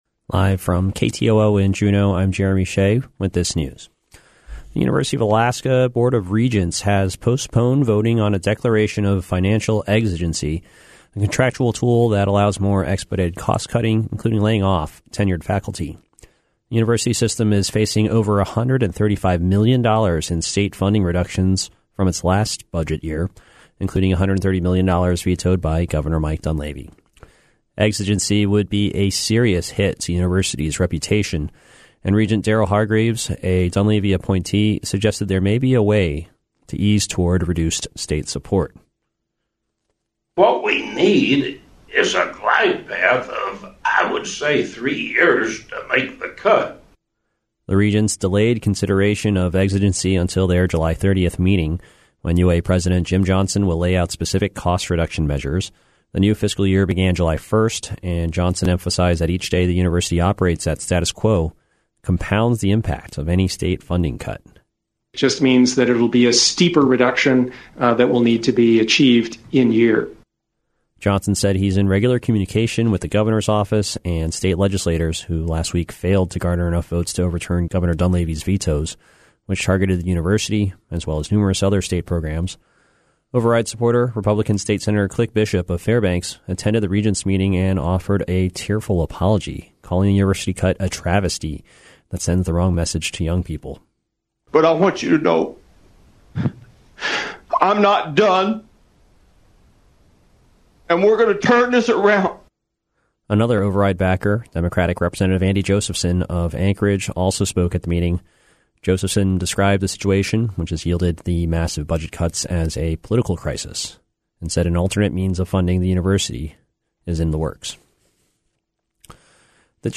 Newscast - Monday, July 15, 2019